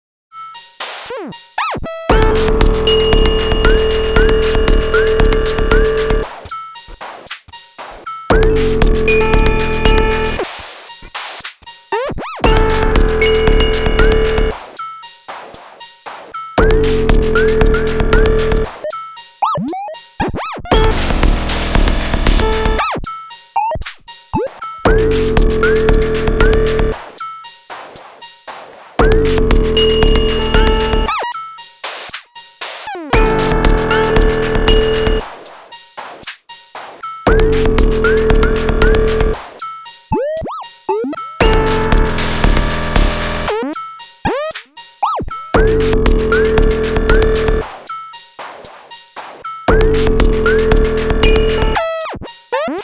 yes you are right, I did some of that stuff today and modulated parameter via OTs midi lfos and noodled one of the presets away. this is a sketch from todays session